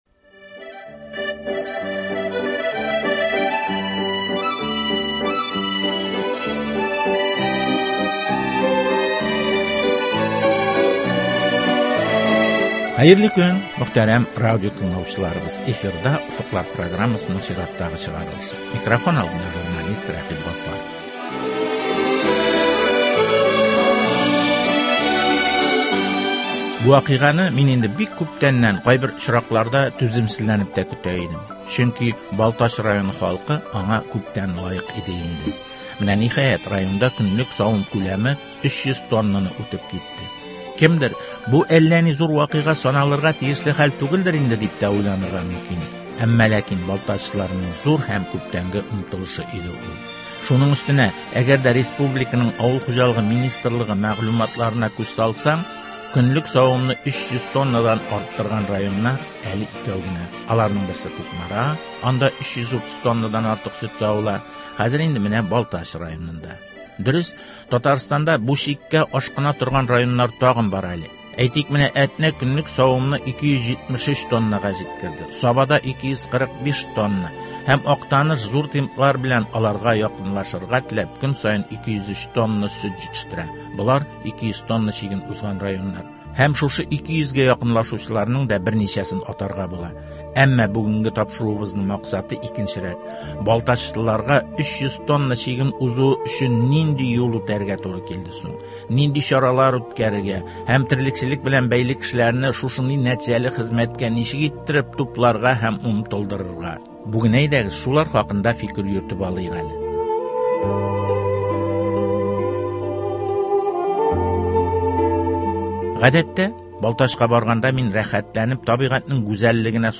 Балтач районы хакимият башлыгы Рамил Нотфуллин белән районның терлекчелектәге проблемалары, аларны чишү юллары һәм ирешелгән казанышлар хакында әңгәмә.